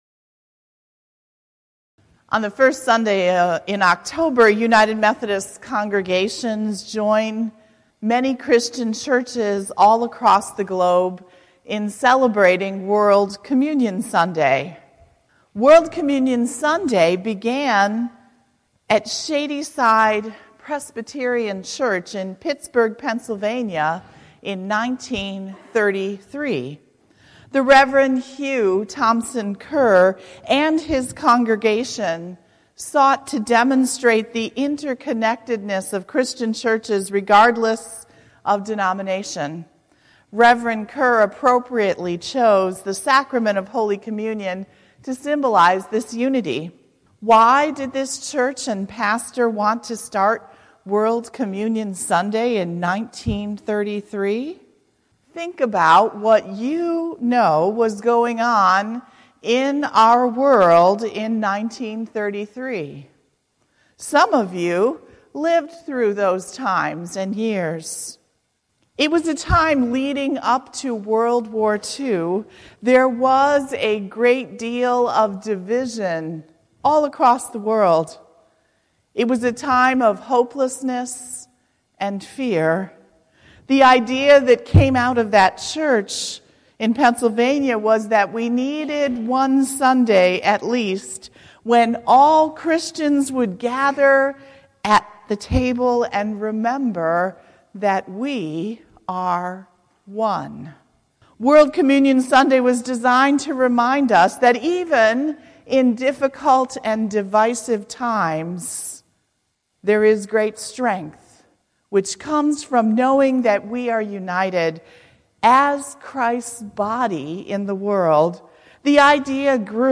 Oct0718-Sermon.mp3